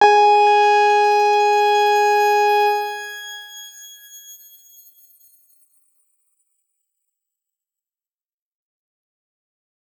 X_Grain-G#4-mf.wav